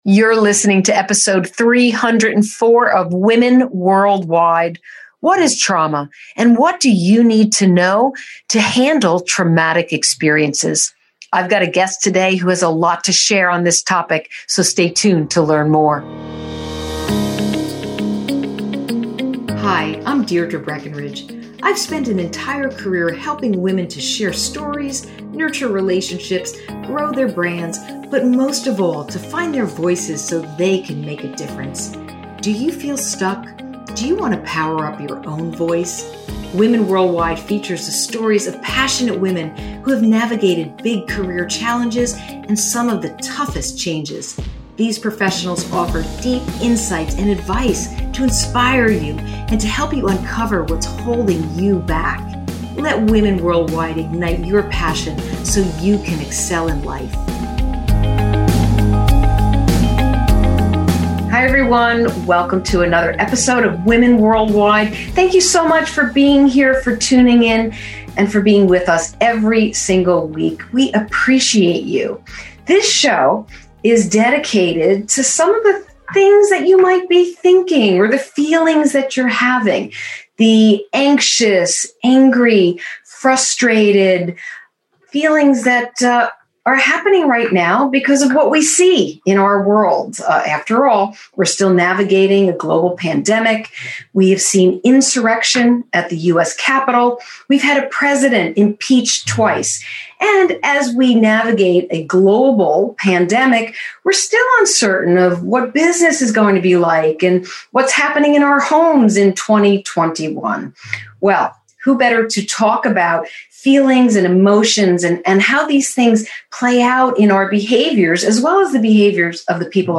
This was an amazing conversation and extremely timely considering trauma can even be caused by national events.